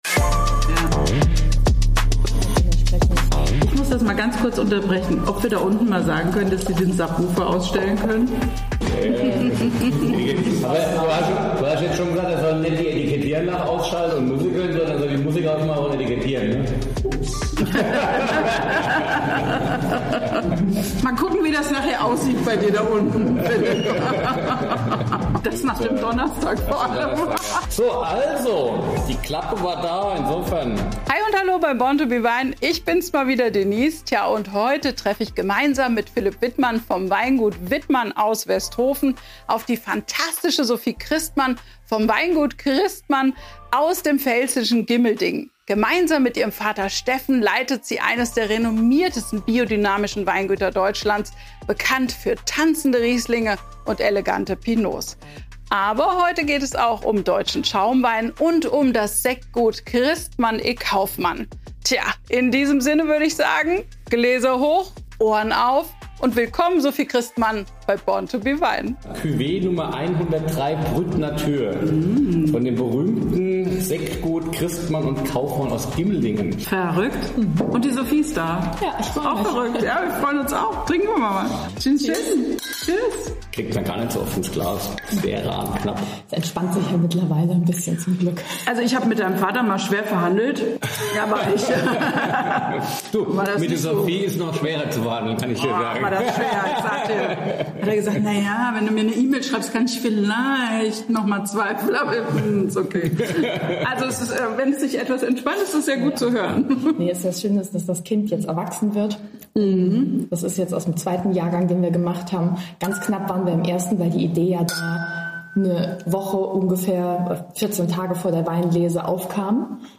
Deep Talk unter Freunden eben und Großes im Glas. Macht es Euch gemütlich und genießt dieses launige Gespräch mit vielen Geschichten, Anekdoten und spannenden Insights.